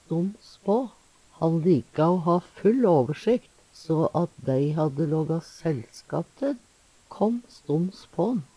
DIALEKTORD PÅ NORMERT NORSK stoms på uventa, ikkje førebudd Eksempel på bruk Han lika o ha full åversikt.